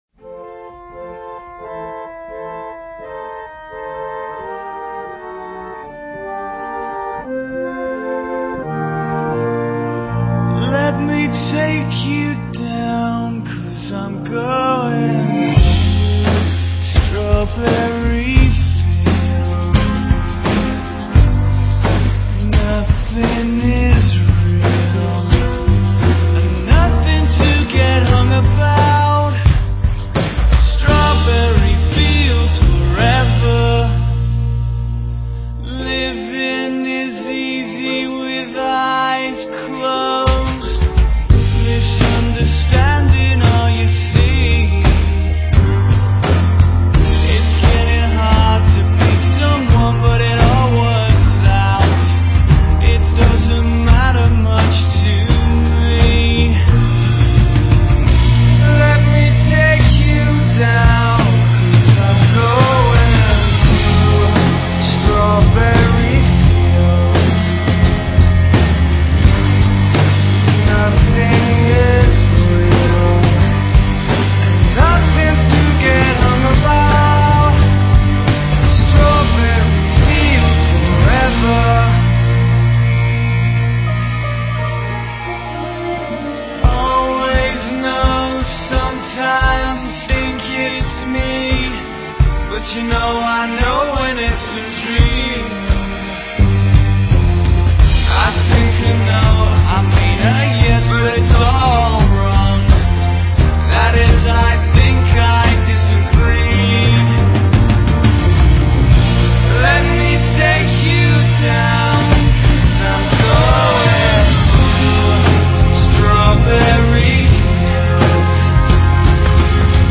Soundtrack